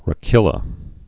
(rə-kĭlə)